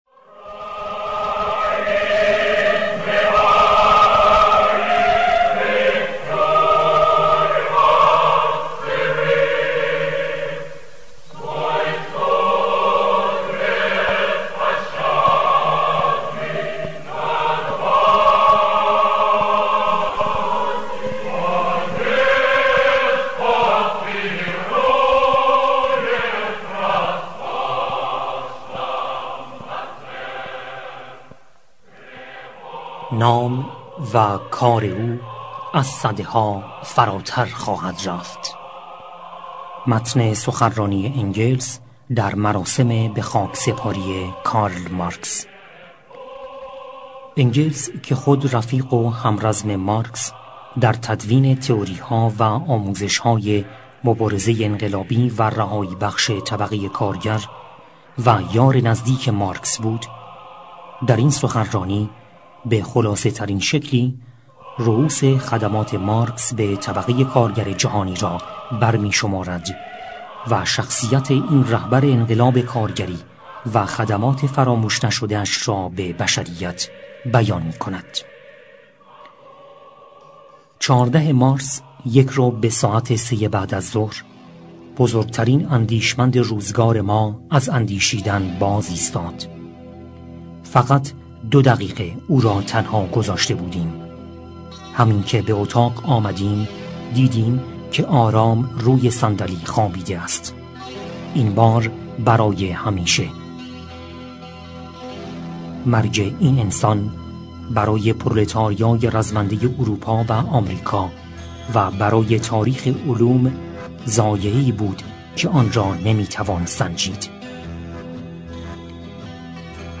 سخنرانی انگلس به هنگام خاکسپاری مارکس